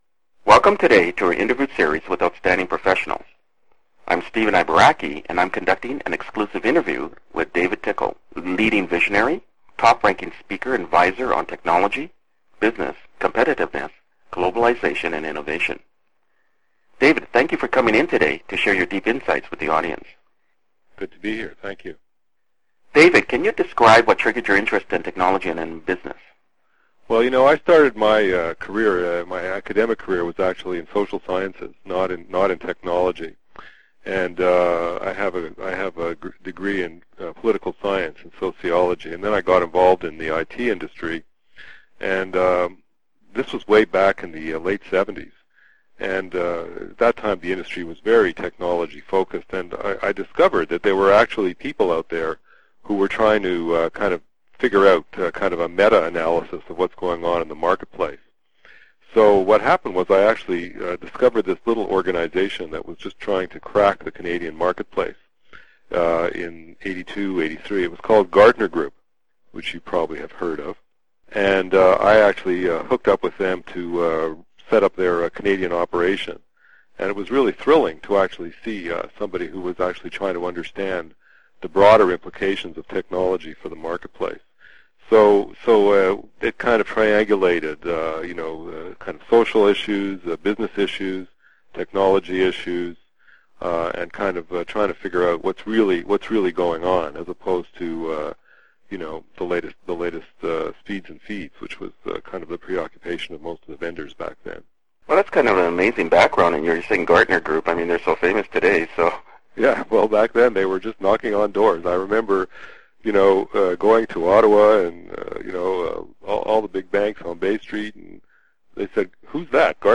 Interview Time Index (MM:SS) and Topic